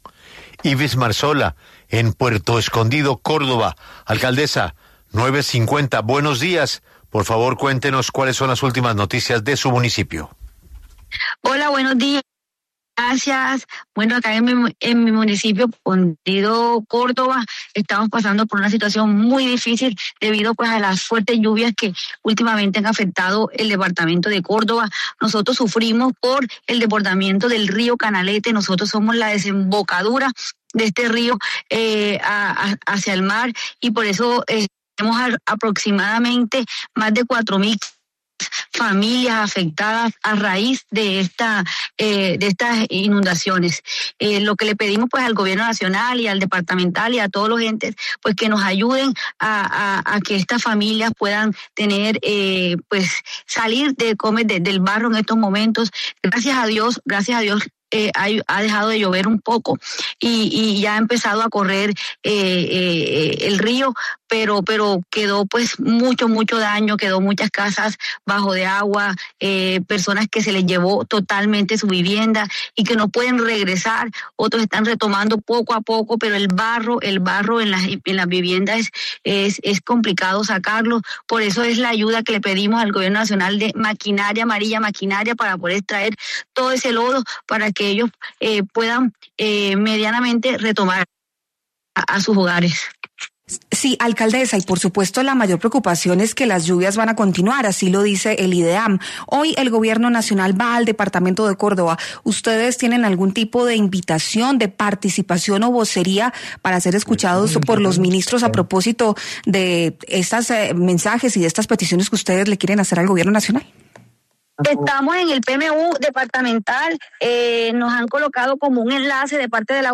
La alcaldesa de Puerto Escondido, Ivis Marzola, habló en 6AM W con Julio Sánchez Cristo, aquí indicó que su municipio está pasando por una situación muy difícil debido a las fuertes lluvias.